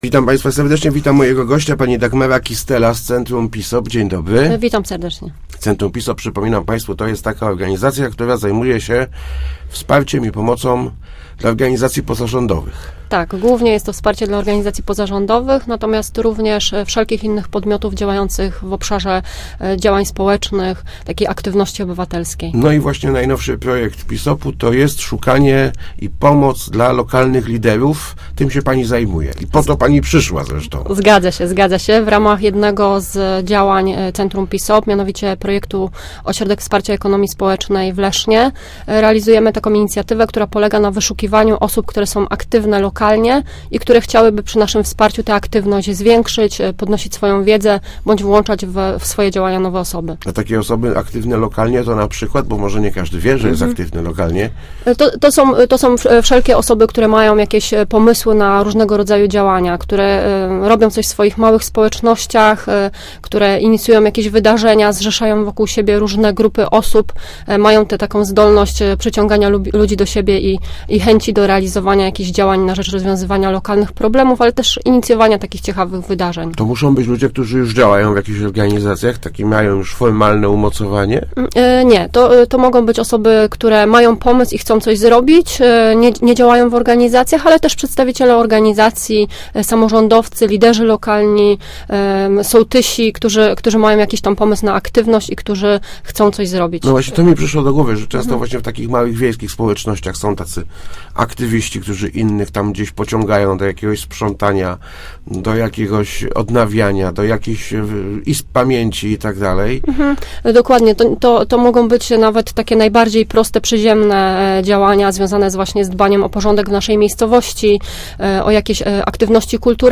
Start arrow Rozmowy Elki arrow PISOP szuka aktywnych